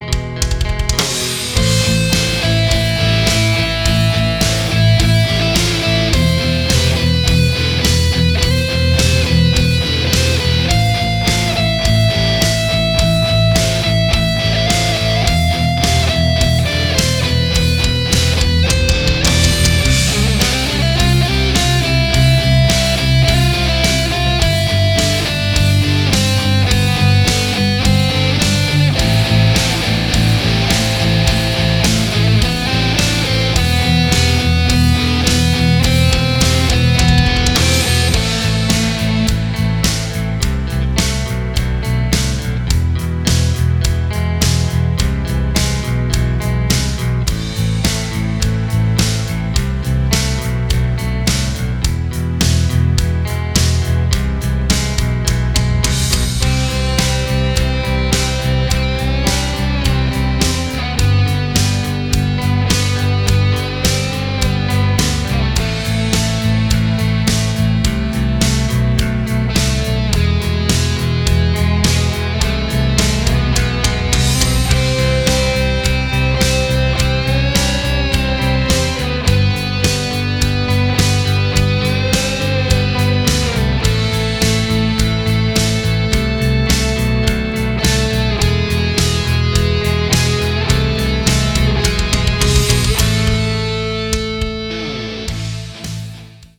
Небольшой отрывок из будущего минуса.
Metal Version 2019